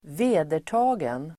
Uttal: [²v'e:der_ta:gen]